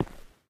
Terrarum/assets/mods/basegame/audio/effects/steps/ROCK_7.ogg at b2ea61aa4dc8936b2e4e6776bca8aa86958be45d
ROCK_7.ogg